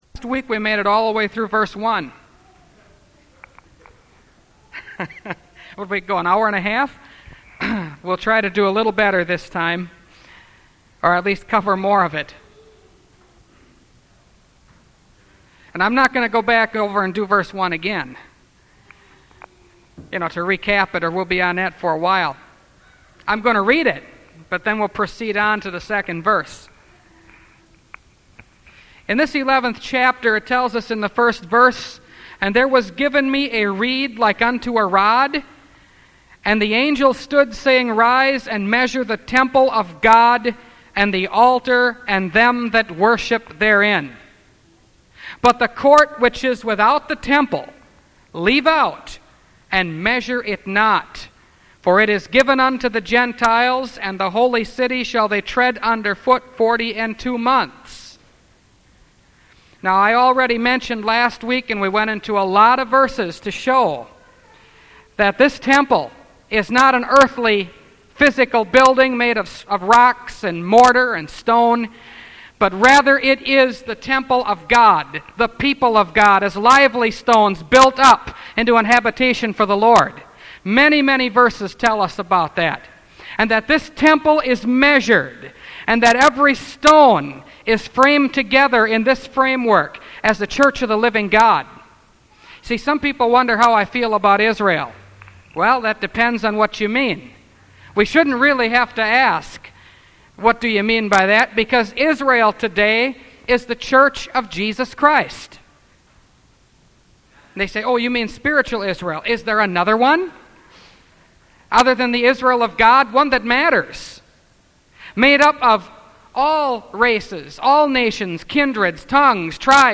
Revelation Series – Part 14 – Last Trumpet Ministries – Truth Tabernacle – Sermon Library